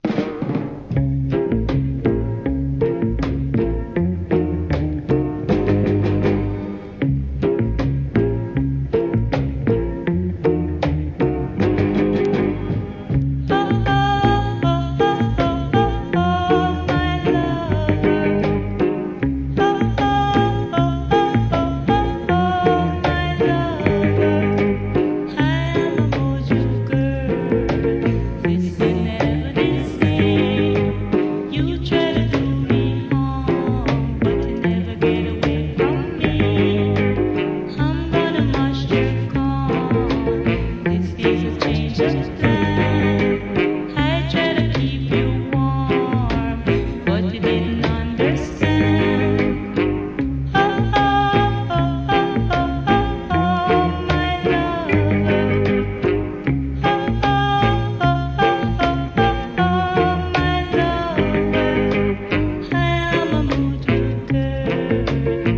¥ 1,980 税込 関連カテゴリ REGGAE 店舗 ただいま品切れ中です お気に入りに追加 1989年、